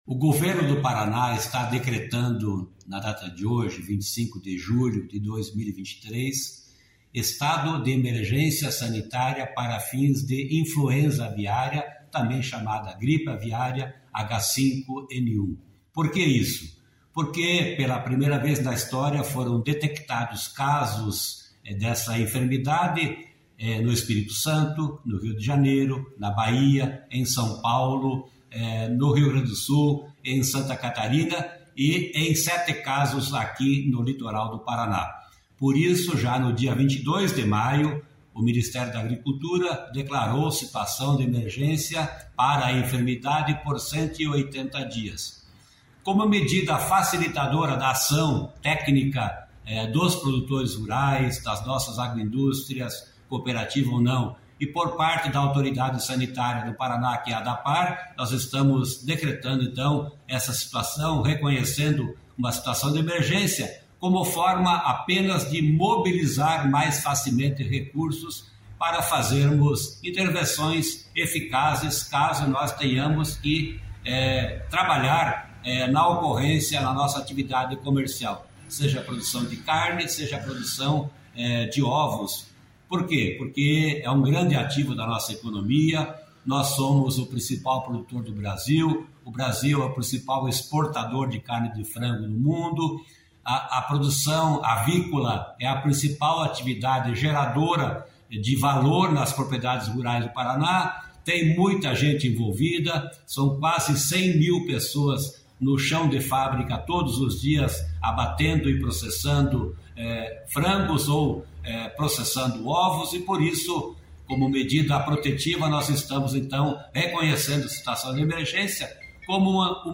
Sonora do secretário Estadual da Agricultura, Norberto Ortigara, sobre o decreto de emergência zoosanitária para gripe aviária de alta patogenicidade no Paraná